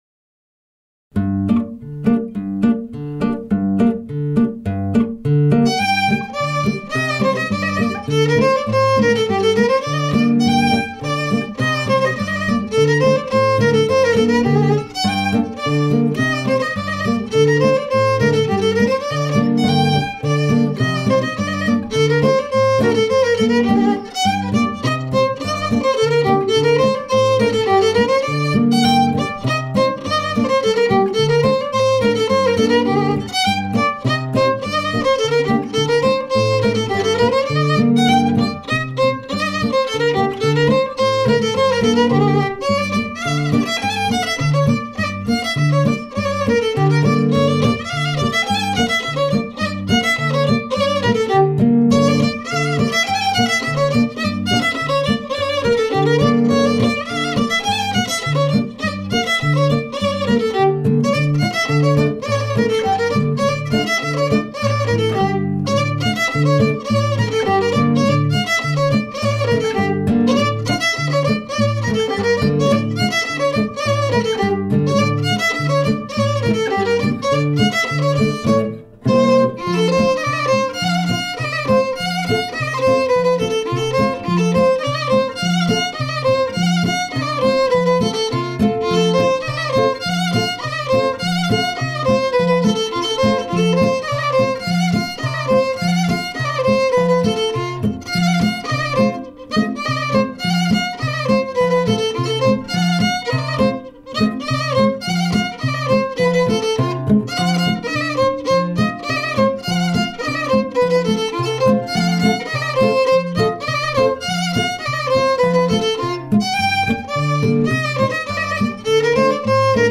Balkan Music From The Danube Arr. Gundula Stojanova Gruen for Bflat Instruments with Backing Tracks – Clifton Edition
This book is written for Clarinet in Bb, Soprano or Tenor Saxophone or Trumpet in Bb.
The recordings closely follow the notation; however, some pieces cannot be fully captured by Western classical notation, and others require flexibility in phrasing and ornamentation.